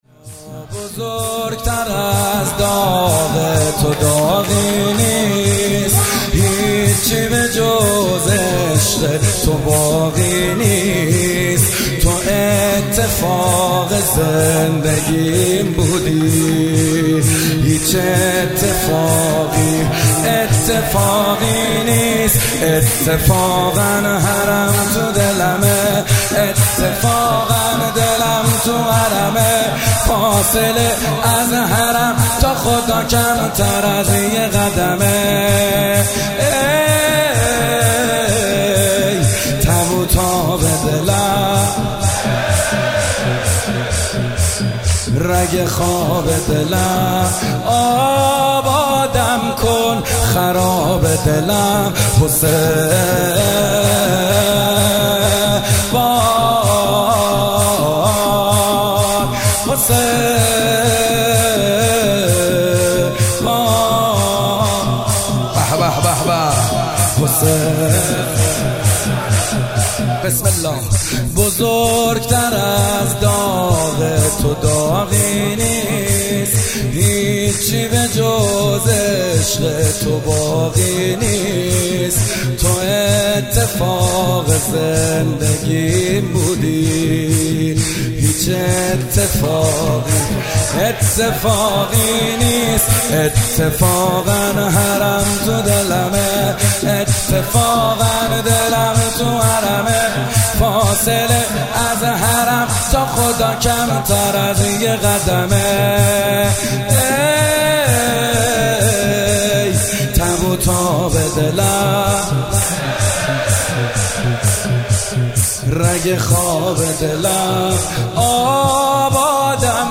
کربلایی محمدحسین حدادیان
محرم97
شب اول محرم97 شور محمد حسین حدادیان